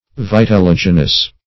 Search Result for " vitelligenous" : The Collaborative International Dictionary of English v.0.48: Vitelligenous \Vit`el*lig"e*nous\, a. (Zool.)